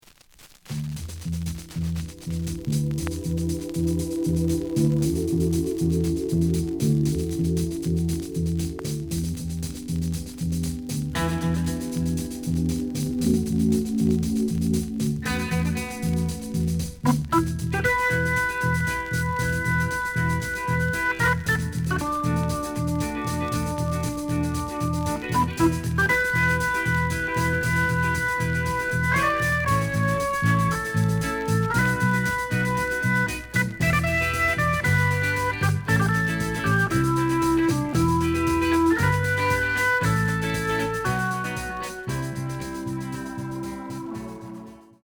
The listen sample is recorded from the actual item.
●Genre: Soul, 60's Soul
Some noise on B side.)